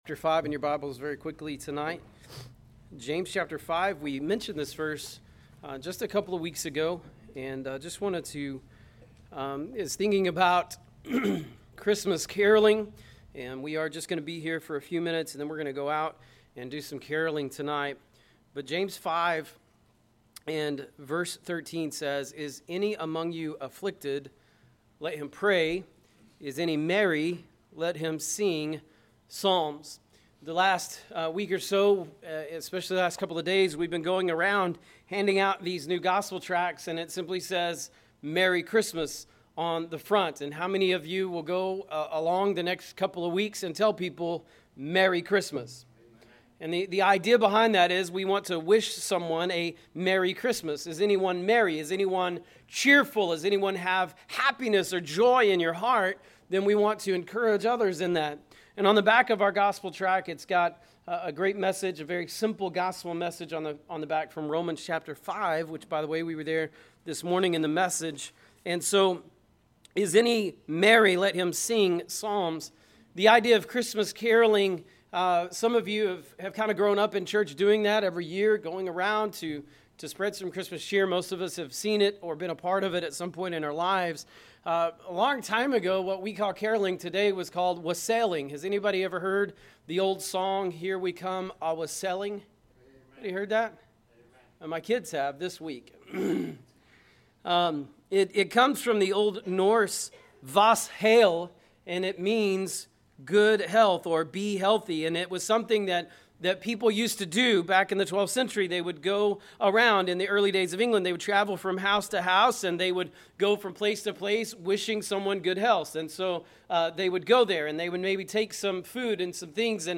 Caroling
Caroling.mp3